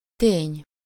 Ääntäminen
Synonyymit faktum Ääntäminen Tuntematon aksentti: IPA: /ˈteːɲ/ Haettu sana löytyi näillä lähdekielillä: unkari Käännös Ääninäyte Substantiivit 1. fait {m} pronunciation of the noun Belgique - en fait